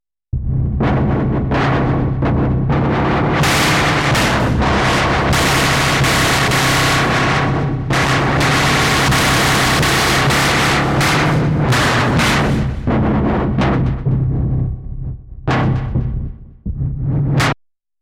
More random sh_t from scratch …